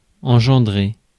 Ääntäminen
IPA : /ɪnˈdʒɛn.də/ IPA : /ɛnˈdʒɛn.də/ US : IPA : /ɛnˈdʒɛn.dɚ/ IPA : /ɪnˈdʒɛn.dɚ/